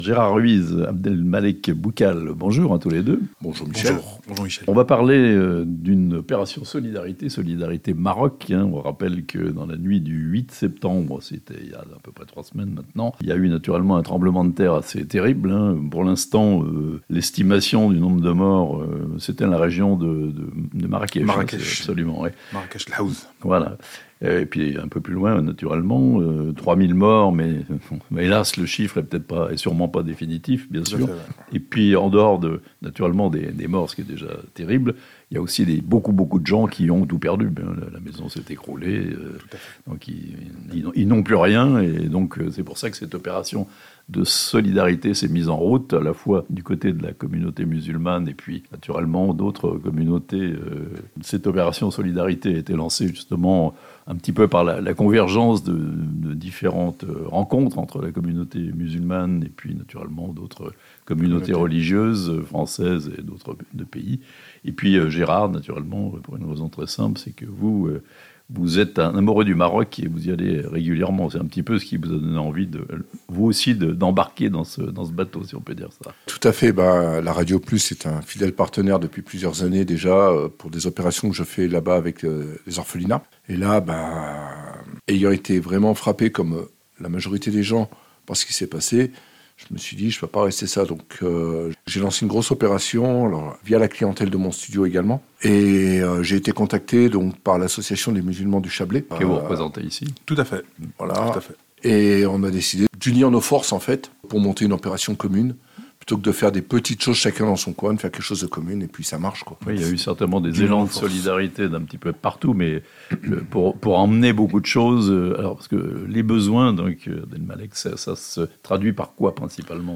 Un bel élan de solidarité des chablaisiens pour aider les populations sinistrées du Maroc (interviews)